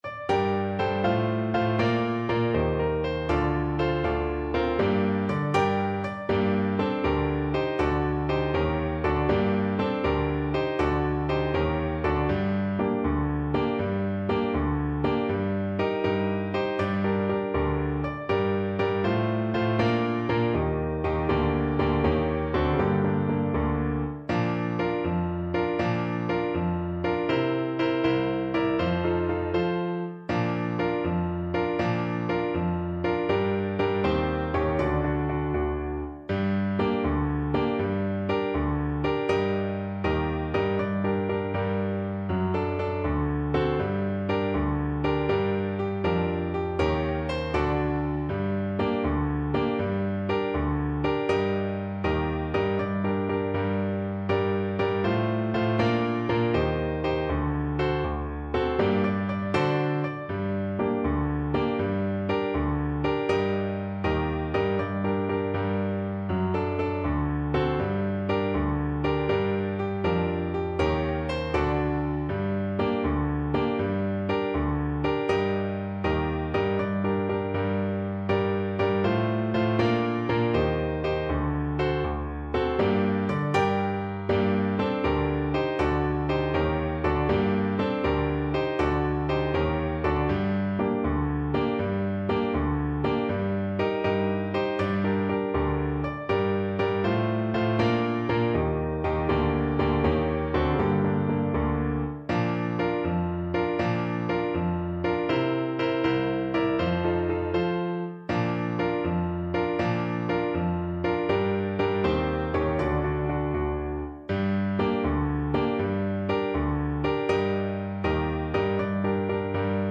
6/8 (View more 6/8 Music)
Brightly, but not too fast